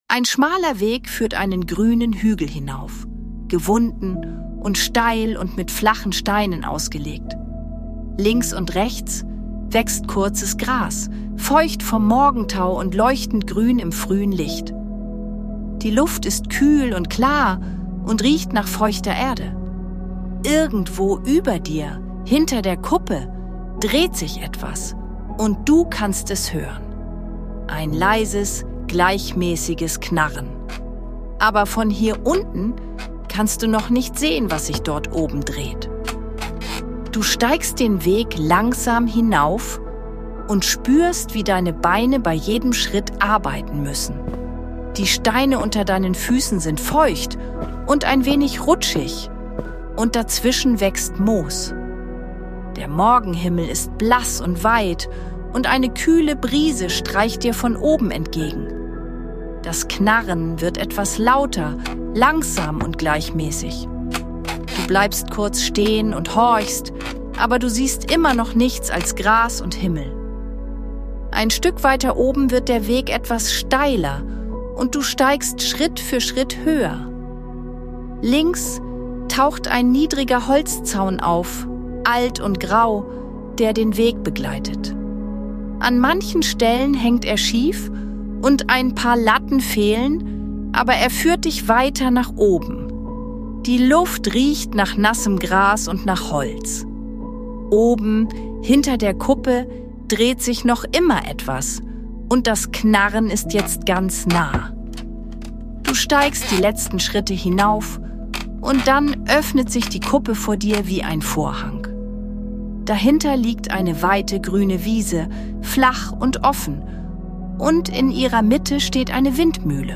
Eine ruhige Fantasiereise zum Malen für Kinder über eine Windmühle auf einer weiten Wiese im Morgenlicht.
Sanfte Fantasiereisen mit leiser Hintergrundmusik – zum Malen und kreativen Entspannen